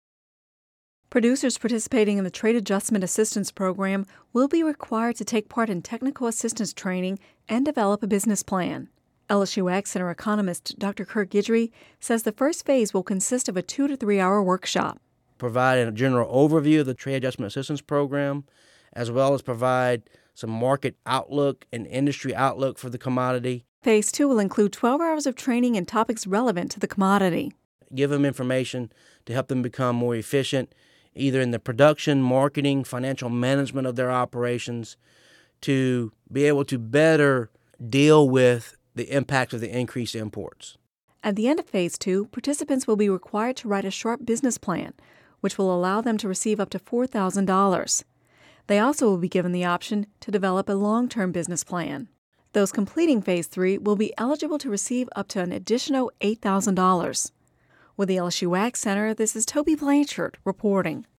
(Radio News 7/26/10) Producers participating in the Trade Adjustment Assistance Program will be required to take part in technical assistance training and develop a business plan.